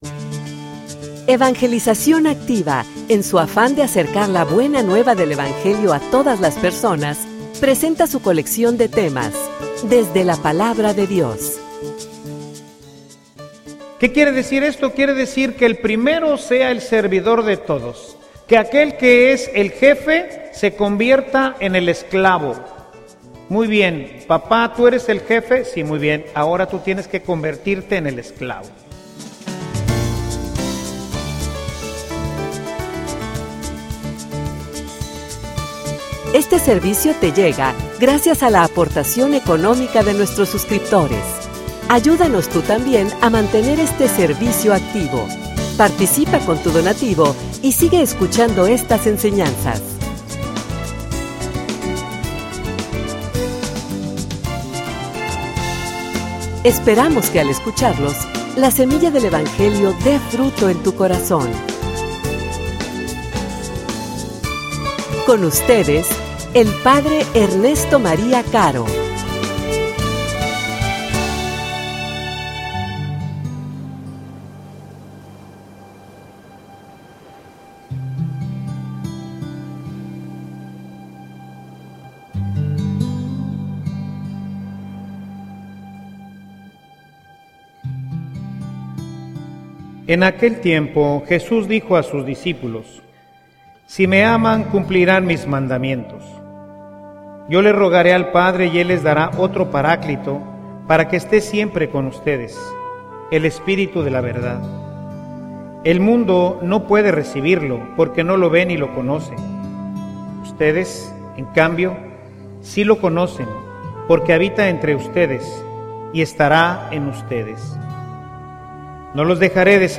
homilia_El_verdadero_amor.mp3